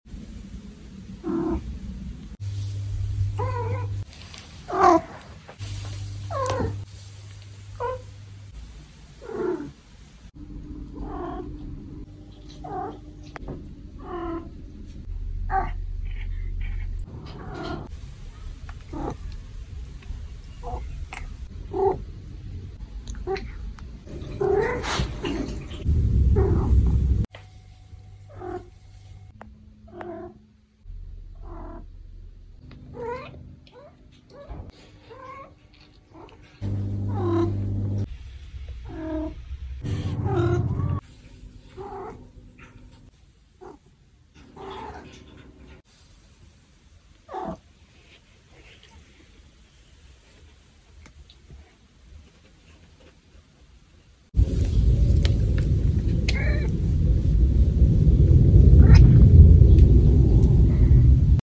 🔊 Maine Coon Cats Activation sound effects free download
🔊 Maine Coon Cats Activation Noises 😻 Long Compilation with our best clips ✨